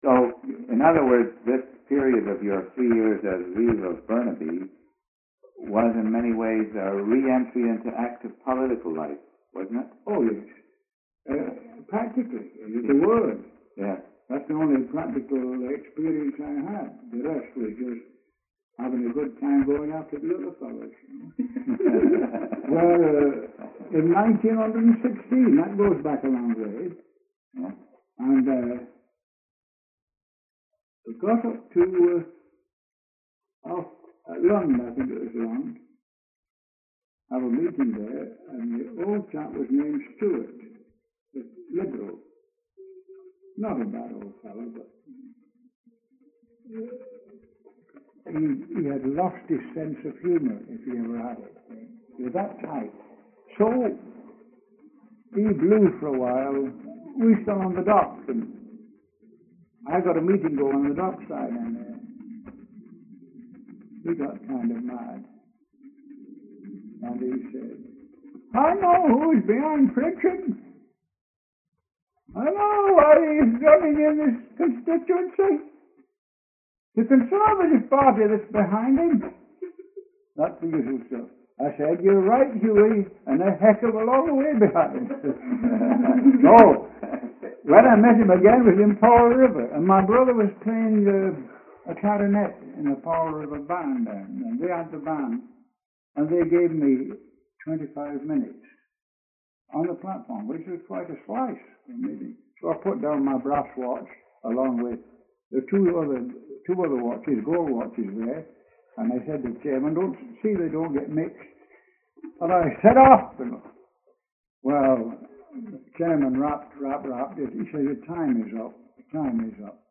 William A. Pritchard reminisces about his experiences as Reeve of Burnaby, B.C. from 1932-1935 and his involvement in left wing politics in the 1930s in Western Canada. The discussion also refers to many individuals who were members of the Socialist Party in Canada and in the United Kingdom in the early twentieth century.,